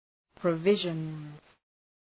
Προφορά
{prə’vıʒənz}